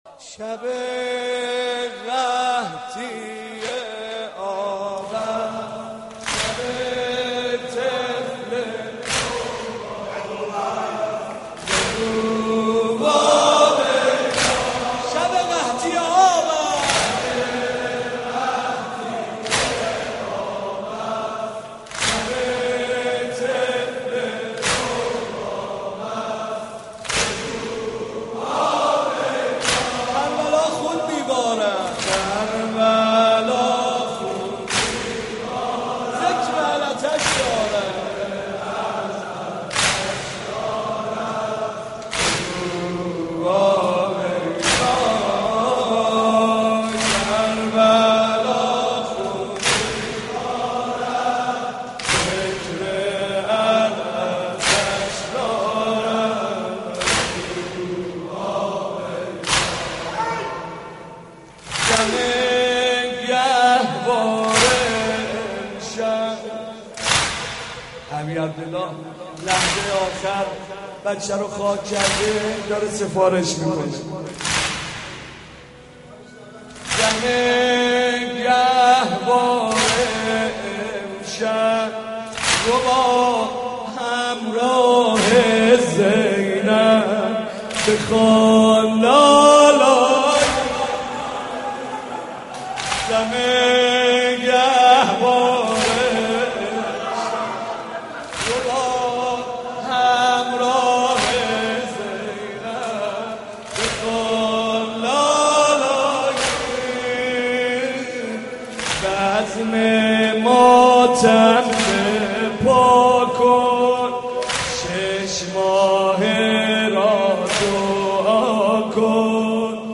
محرم 88 - سینه زنی 5